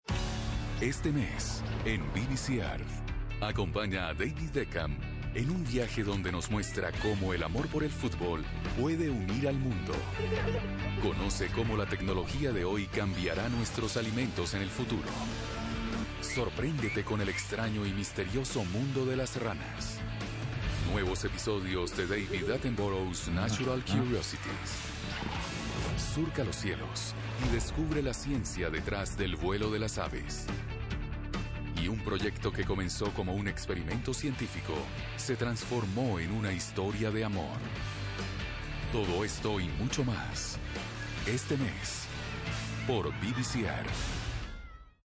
Natural, elegant and warm voice.
kolumbianisch
Sprechprobe: Werbung (Muttersprache):